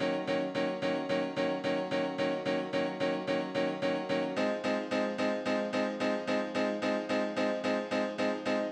03 Piano PT2.wav